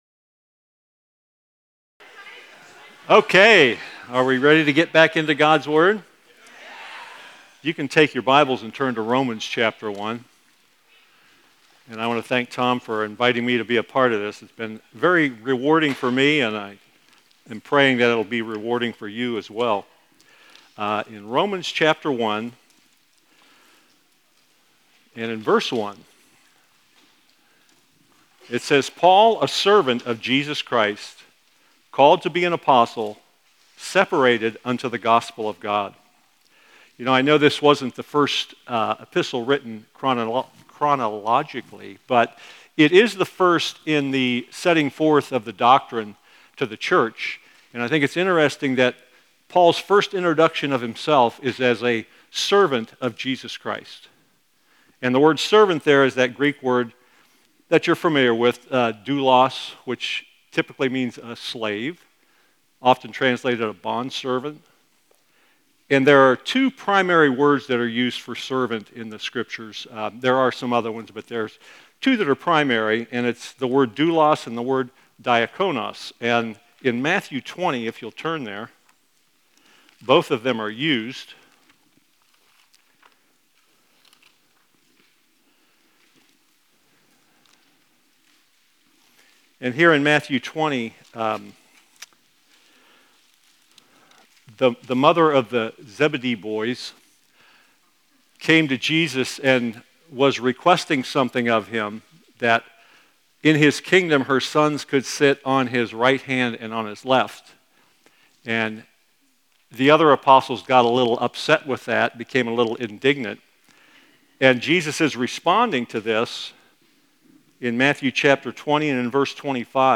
(Family Camp 2025)